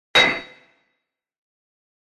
На этой странице собраны звуки кузницы и работы с наковальней: ритмичные удары молота, звон металла, фоновый гул мастерской.
Громкий звон удара наковальни по стальной заготовке в кузнице